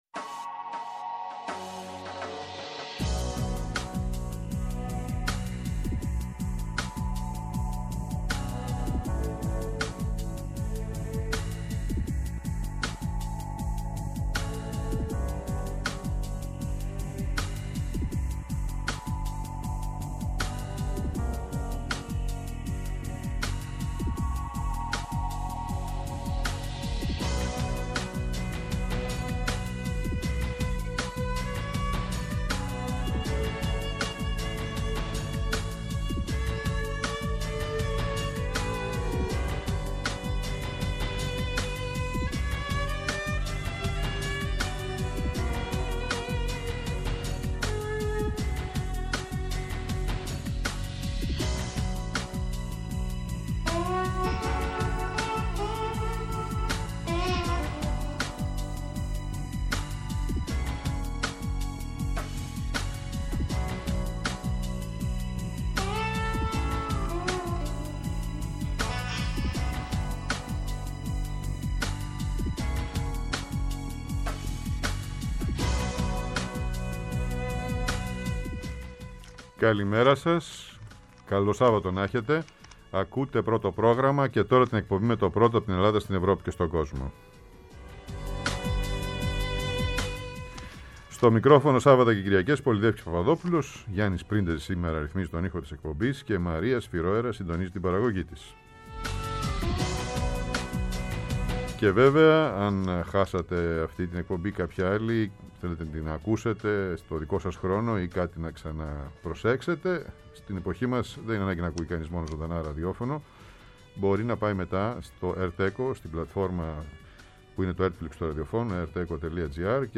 Σύνταξη-παρουσίαση